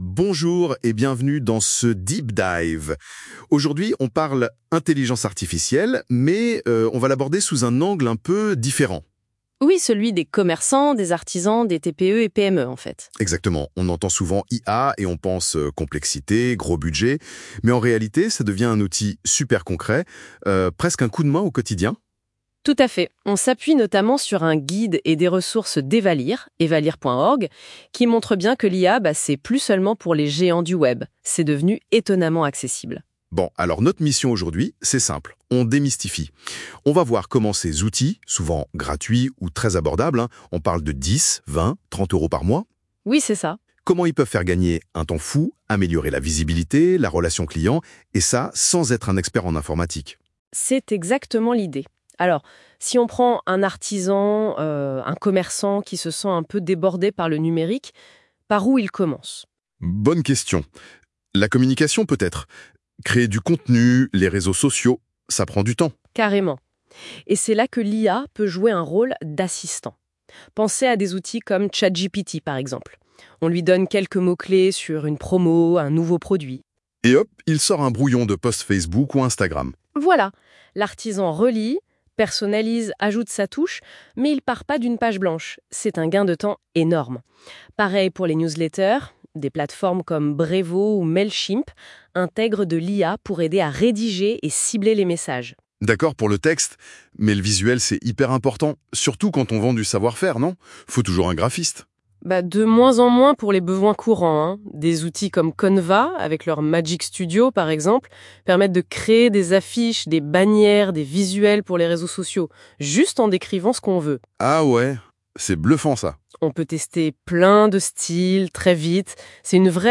Notre interview par IA